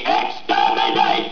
Great voices though
exterminate.wav